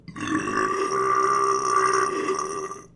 随机" sfx大声敲击
描述：一声巨响敲门
Tag: 敲的门 大声的